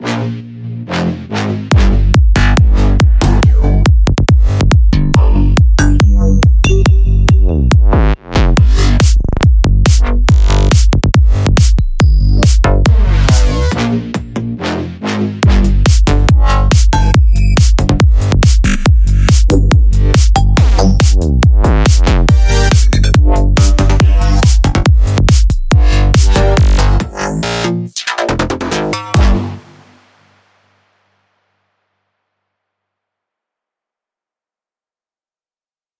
如果您需要一些新鲜的Bass，Bone Shot和Bass Shots供您制作，Jilax Serum Presets是您可以选择的套装。
演示包中的所有声音均作为预设或样本提供。